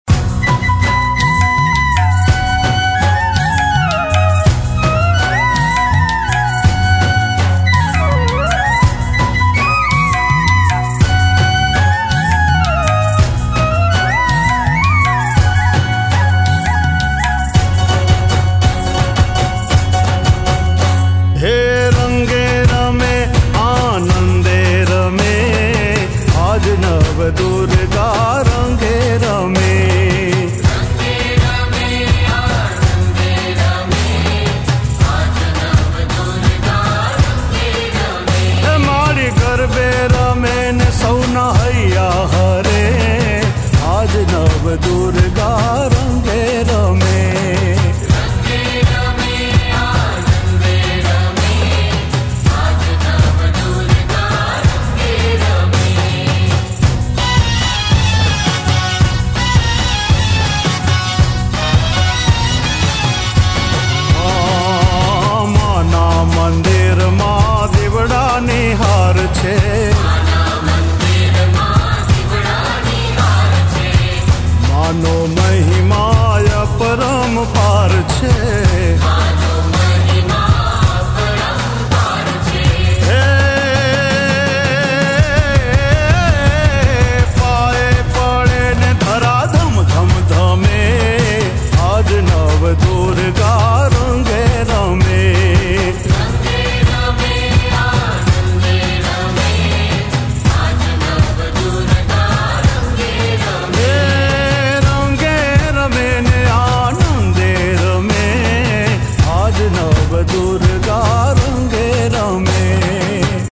Navratri Garba Albums